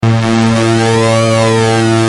Free MP3 vintage Sequential circuits Pro-5 loops & sound effects 2